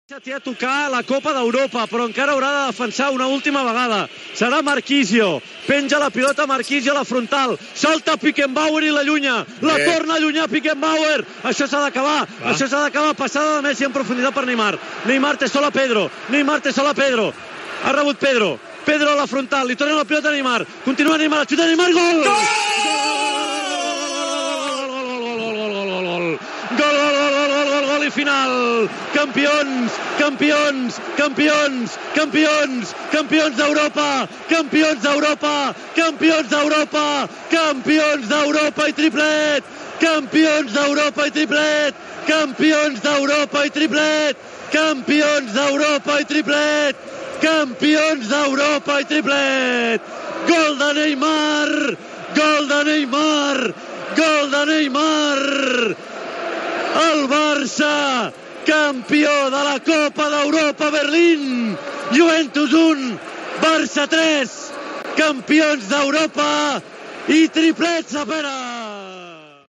Transmissió de la final de la Copa d'Europa de futbol masculí, des de l'Olympiastadion de Berlín, entre el Futbol Club Barcelona i la Juventus de Milàs.
Narració del gol de Neymar (3-1)
Esportiu